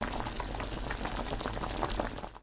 油炸.mp3